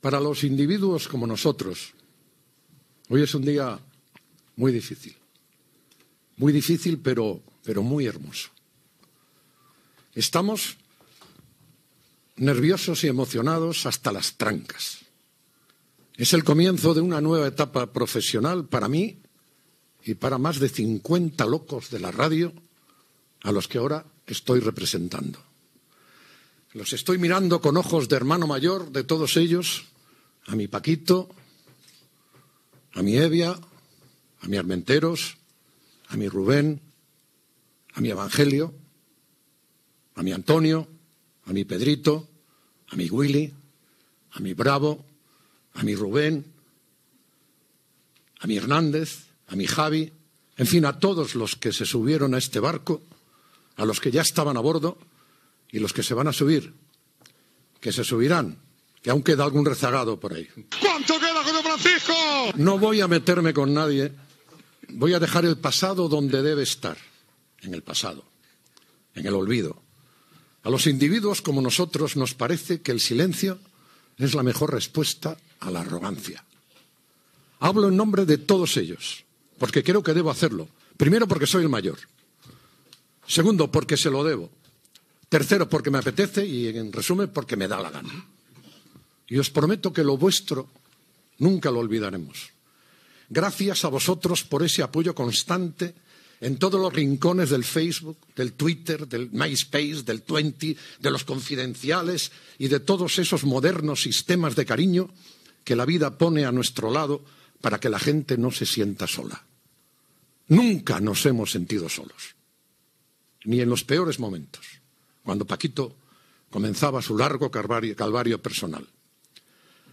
Paraules de presentació de Pepe Domingo Castaño en el primer programa que fa l'equip vingut de la Cadena SER, "hola, hola", identificació del programa, noms de l'equip, identificació de la cadena i dóna pas a Paco González, aplaudiment, crit de Rubén Martín, repàs de la programació esportiva, agraïment a la COPE i a les empreses que posaran publicitat al programa